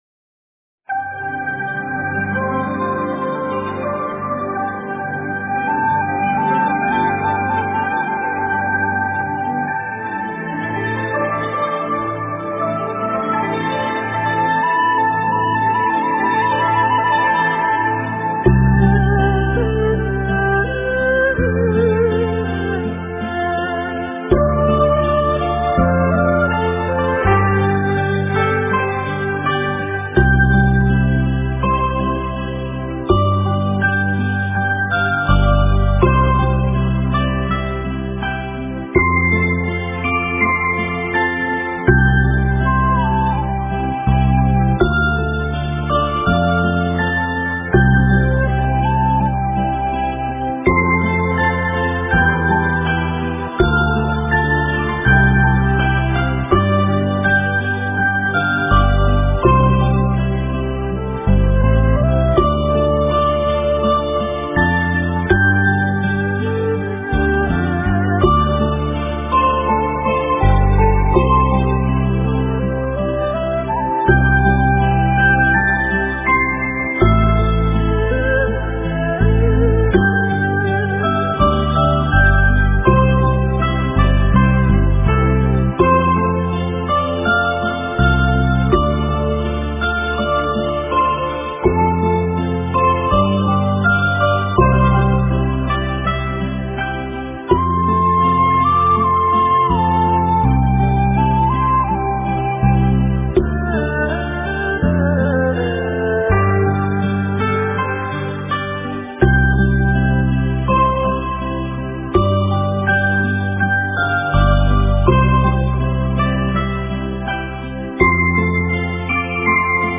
观音灵感真言+心经--水晶佛乐 冥想 观音灵感真言+心经--水晶佛乐 点我： 标签: 佛音 冥想 佛教音乐 返回列表 上一篇： 幽寿佛音--佛曲 下一篇： 风轻云淡--佛曲 相关文章 荷塘风缓--王俊雄 荷塘风缓--王俊雄...